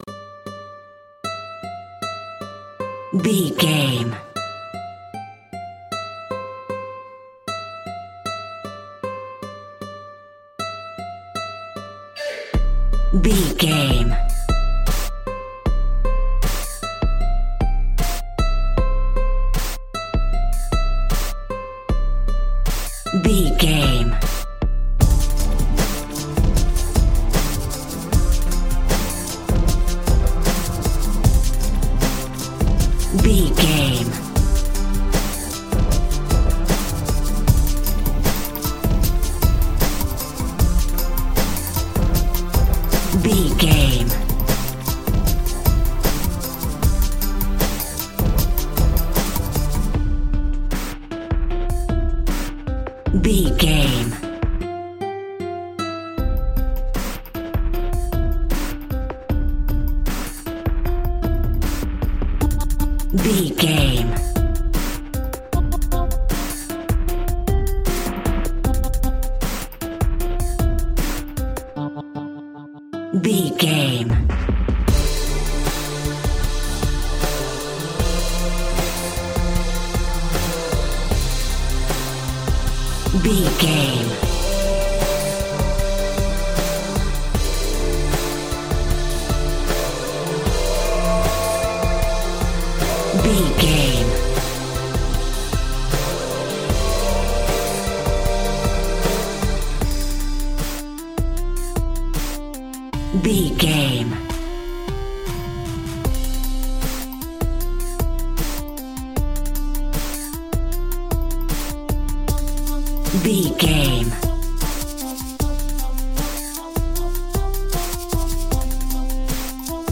Melody Drum and Bass Music Cue.
Fast paced
Aeolian/Minor
dark
futuristic
groovy
harp
drum machine
piano
electronic
synth lead
synth bass